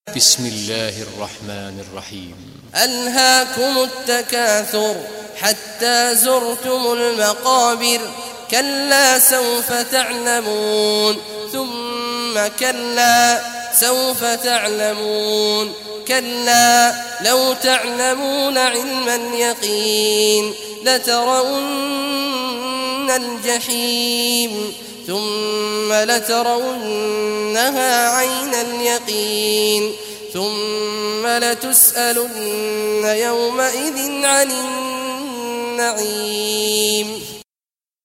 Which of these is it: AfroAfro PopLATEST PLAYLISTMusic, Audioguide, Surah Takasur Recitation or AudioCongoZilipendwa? Surah Takasur Recitation